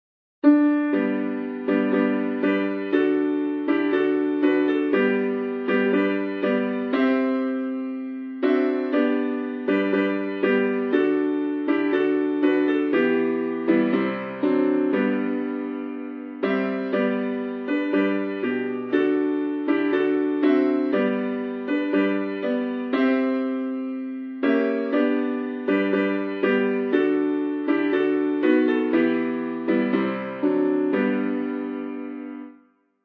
BG: Auld Lang Syne (Piano) MP3
AH_BG_Auld_Lang_Syne_Piano.mp3